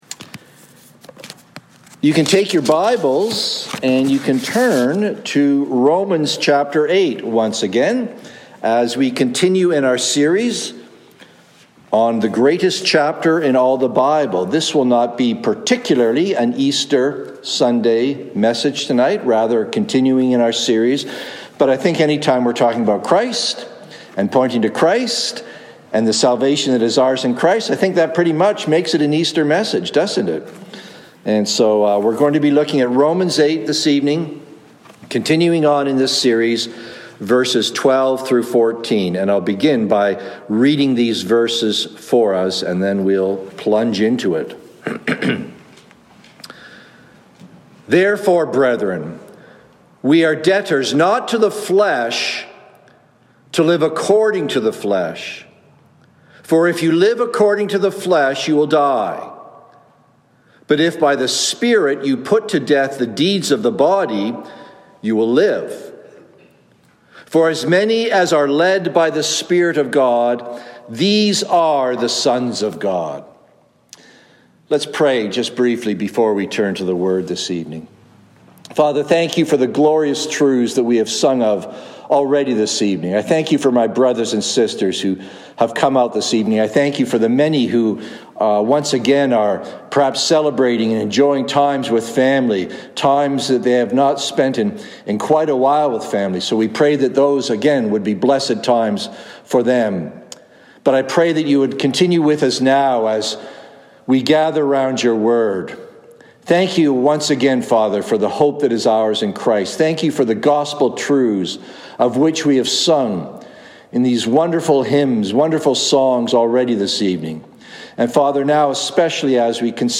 "Led By the Spirit" (Evening Service)
Download Download Reference Rom 8:12-14 Sermon Notes April9-23PM.docx From this series "Who Shall Separate?"